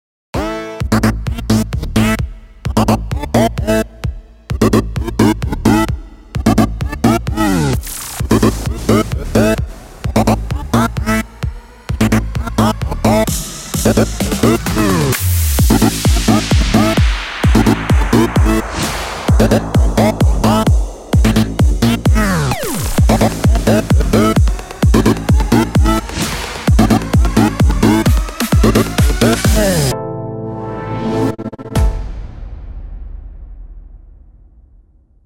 ה - EDM הכי טוב שהוצאתי עד היום …
איזה ערכים כתבת בחיתוכים טריולה על 32?